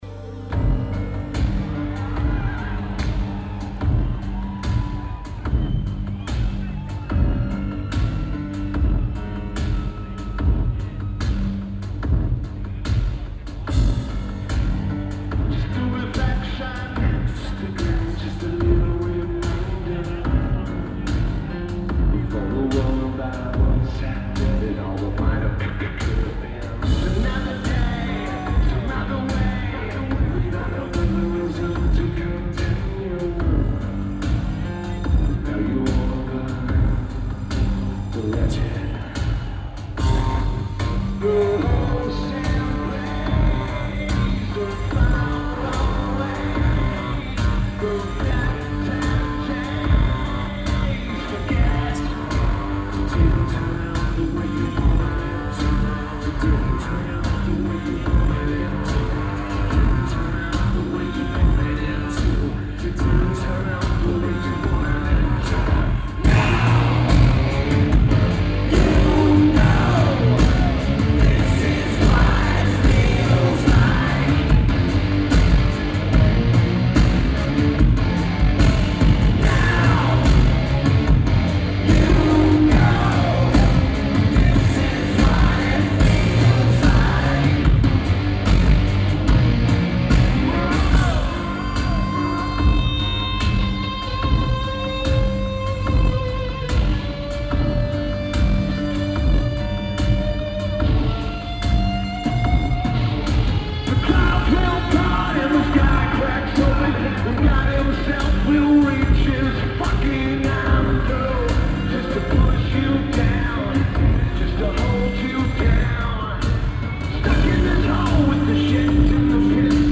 Rod Laver Arena
Keyboards/Bass/Backing Vocals
Drums
Guitar
Vocals/Guitar/Keyboards
Lineage: Audio - AUD (Soundman OKM II Rock + Sony PCM-M10)